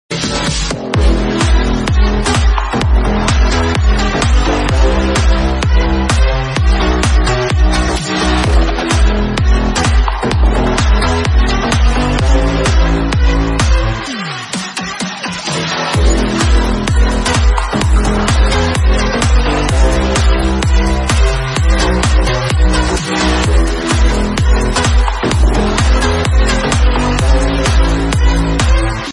Dance
Electronic